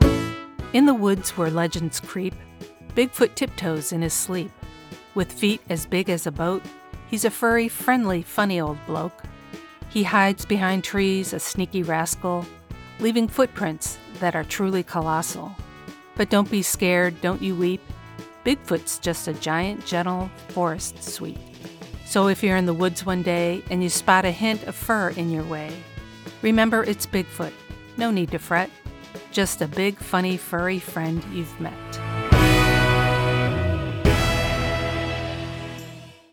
Professional Female Voice Over Talent
Poem Sample
Let’s work together to bring your words to life with my conversational, authoritative and articulate voice.